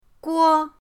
guo1.mp3